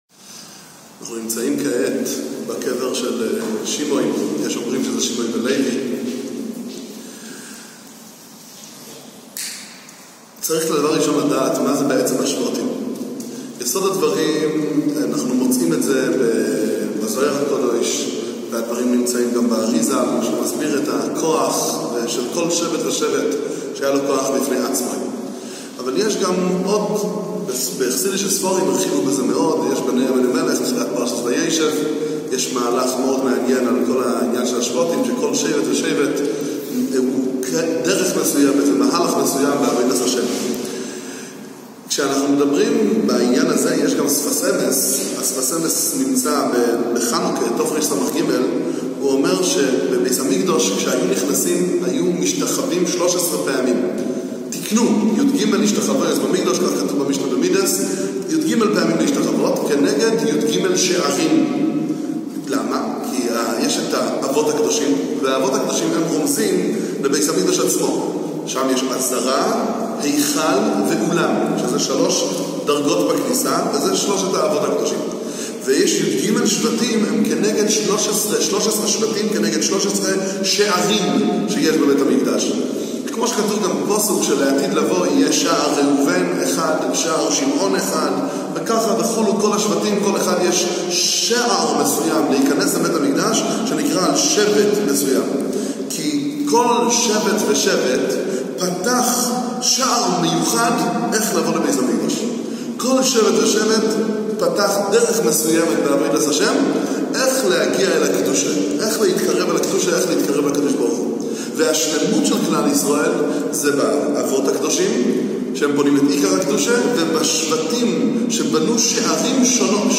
דבר תורה על שבט שמעון והקשר לרבי שמעון בר יוחאי, רשב"י בדברי רבי צדוק הכהן מלובלין